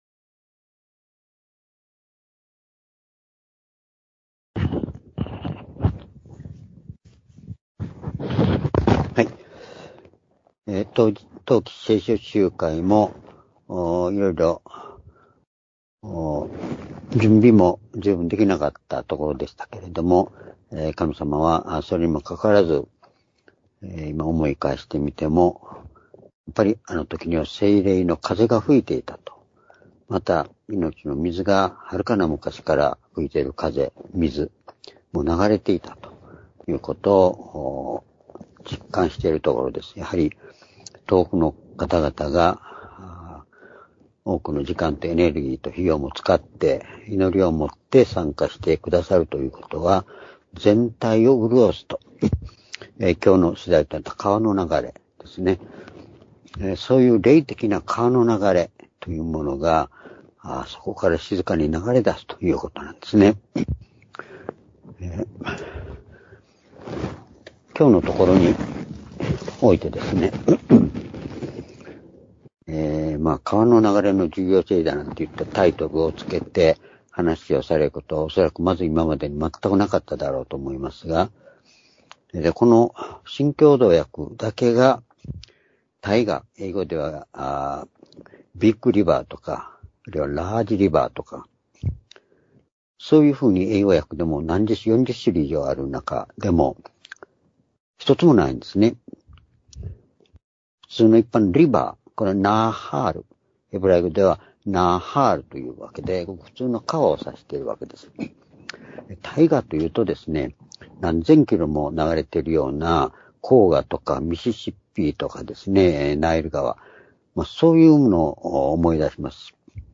（主日・夕拝）礼拝日時 ２０２５年1月7日（夕拝） 聖書講話箇所 「川の流れの重要性」 詩編46の5-7 ※視聴できない場合は をクリックしてください。